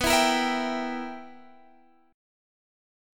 BM7sus4#5 chord